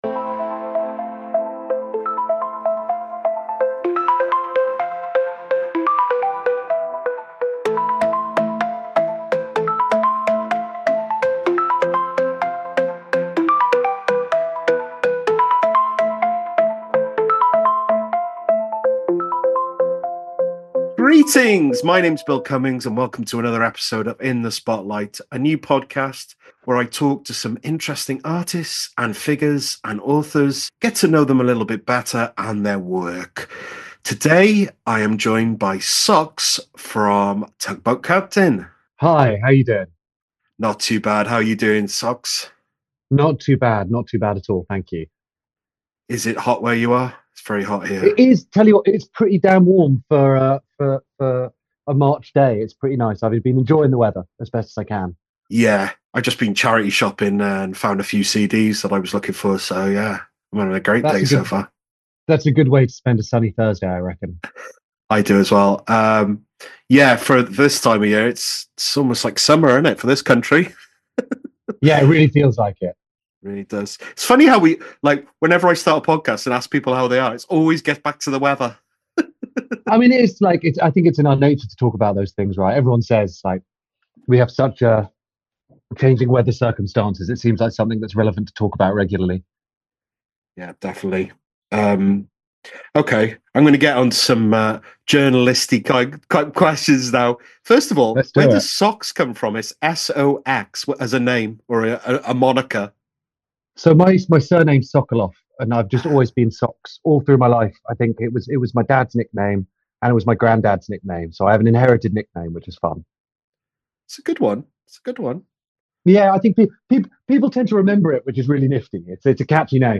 In The Spotlight: Interviews